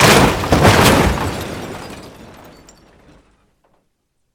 vehicleRam.wav